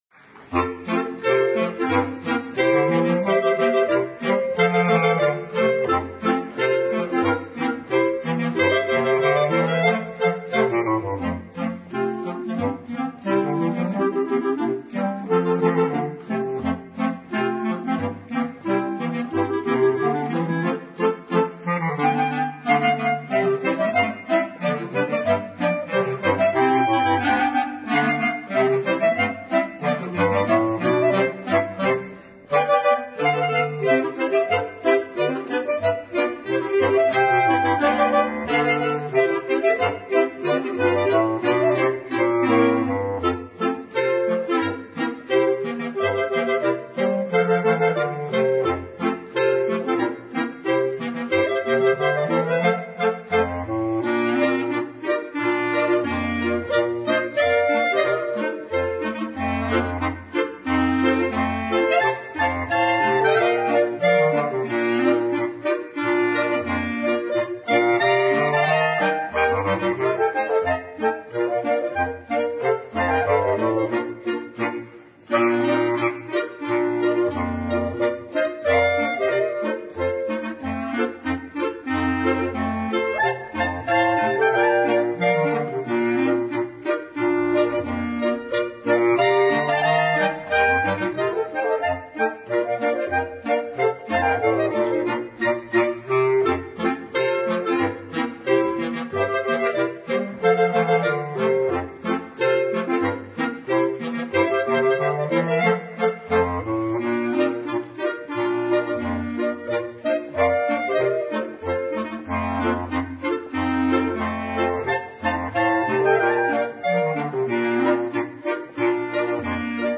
BR-Aufnahme 2025 im Freilandmuseum Neusath